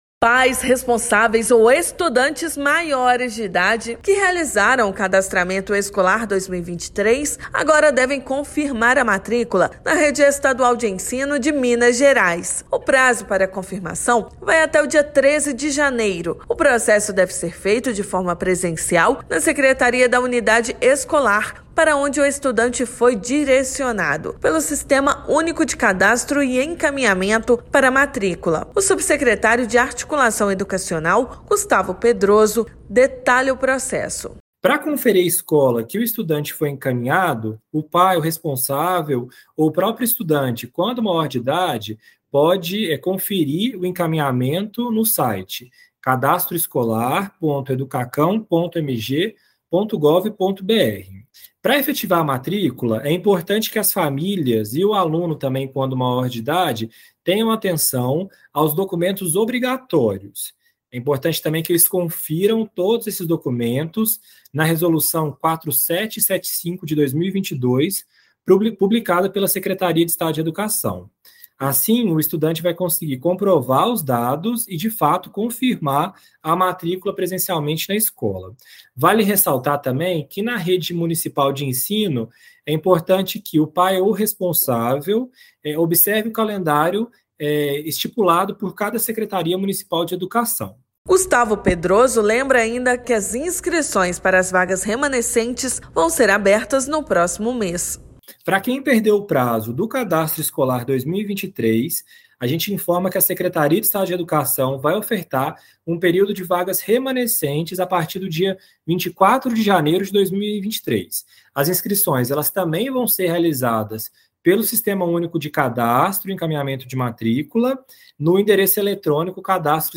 Para realização do processo, é necessário apresentar documentação específica nas unidades escolares. Ouça matéria de rádio.